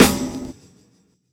Snares
Medicated Snare 5.wav